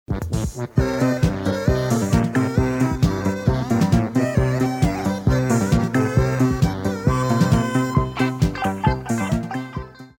Я пытался аутентично подобрать ехидненькое соло, которое там звучит, но к удивлению не смог разгадать эту загадку.